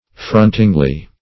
frontingly - definition of frontingly - synonyms, pronunciation, spelling from Free Dictionary Search Result for " frontingly" : The Collaborative International Dictionary of English v.0.48: Frontingly \Front"ing*ly\, adv. In a fronting or facing position; opposingly.